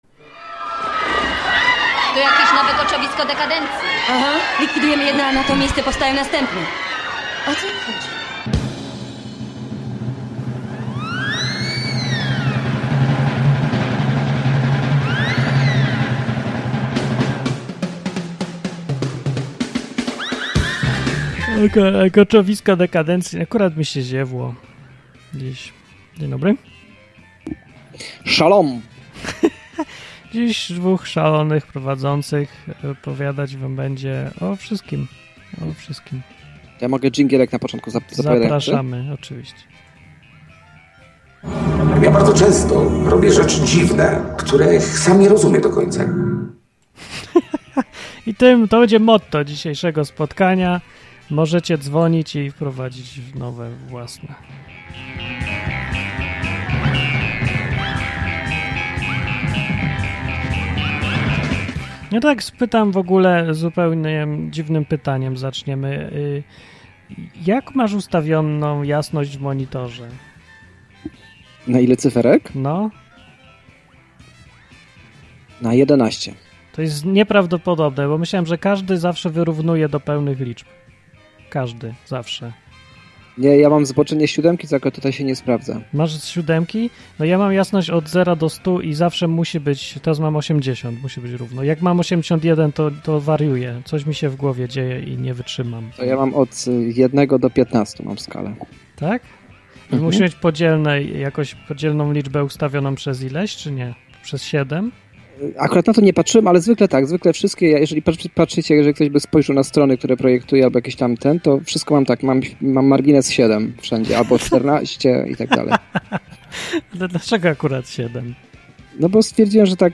Słuchacz opowiada o nowej ciekawej koncepcji: polski narodowy socjalizm.
Koczowisko Dekandencji to dwu-radiowa audycja, w której od luzu, sarkazmu i ironii wióry lecą.